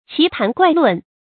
奇談怪論 注音： ㄑㄧˊ ㄊㄢˊ ㄍㄨㄞˋ ㄌㄨㄣˋ 讀音讀法： 意思解釋： 非常古怪又不合情理的談論、文章。